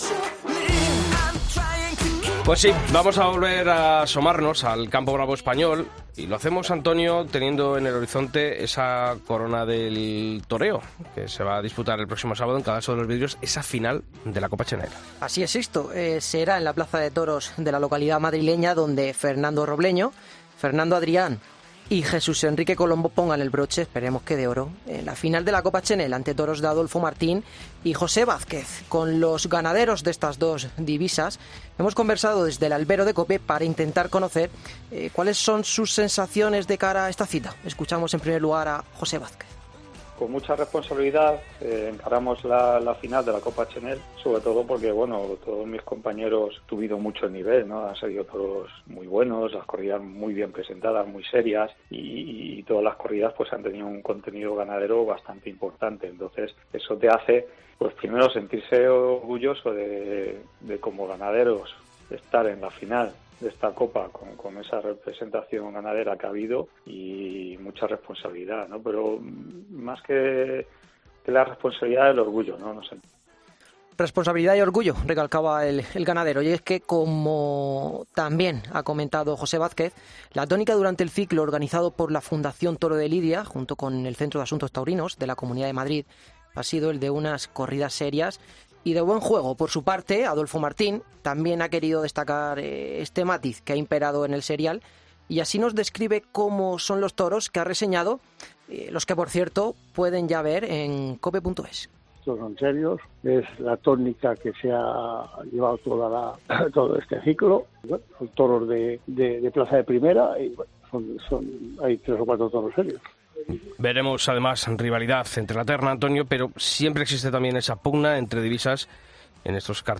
Los ganaderos de la final de la Copa Chenel hablan en el El Albero de los toros reseñados para este festejo y las esperanzas puestas en él.